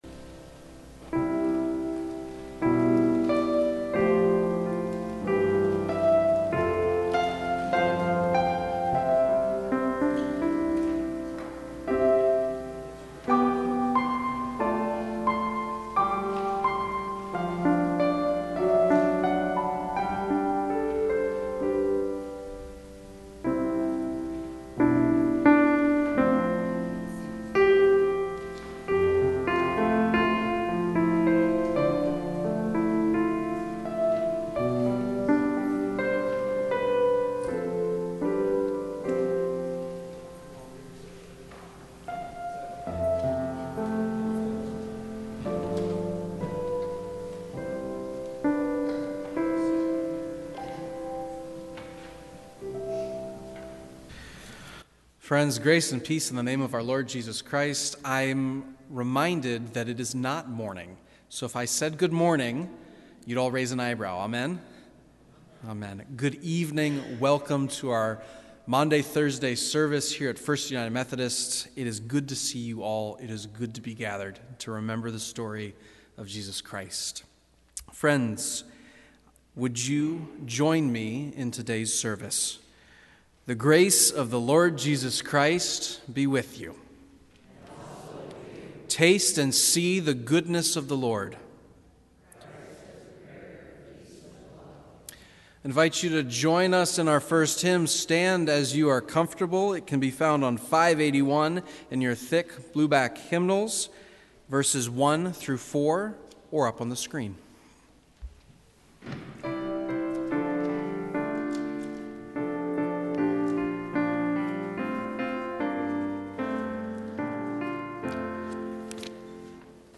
First United Methodist Church Sermon Audio Files